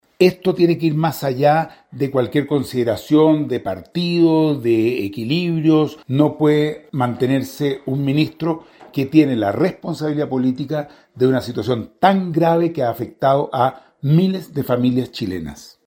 En tanto, para el diputado de Acción Humanista, Tomás Hirsch, esto va más allá de los equilibrios políticos del gabinete, apuntando a que lo realmente significativo es que se asuman las responsabilidades correspondientes.